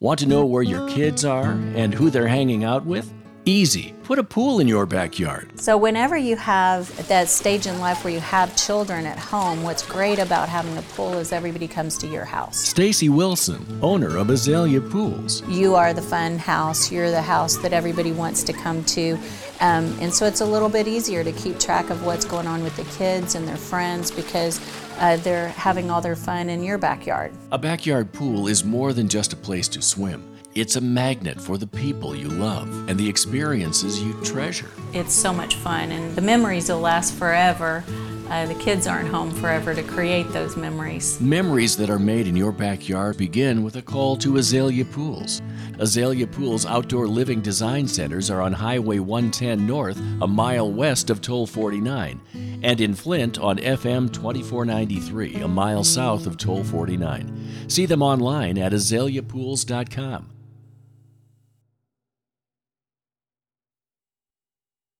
The best local radio spot I heard in 2025.
Developed by ATW Creative in Dallas, this :60 aired on my client station KTBB. This advertiser understands that NOTHING outweighs a parent's concern.